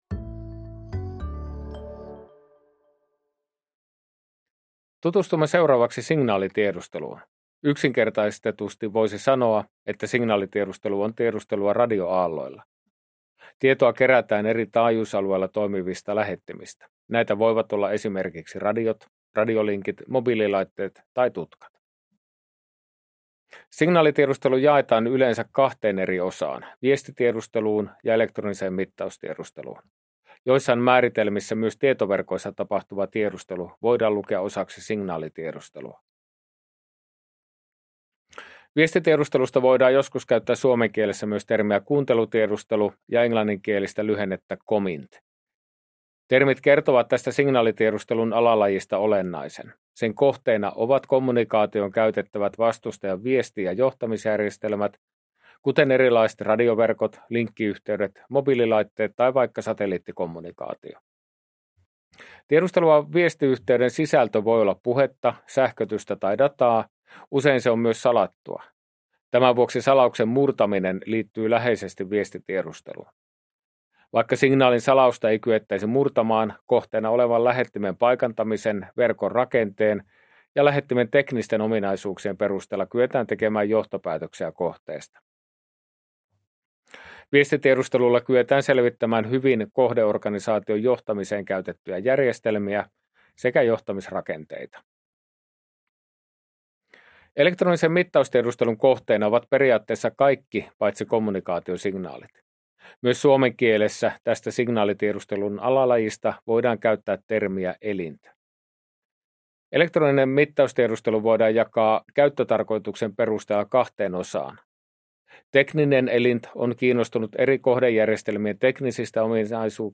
Päivitetty luento lv 25-26 toteutukselle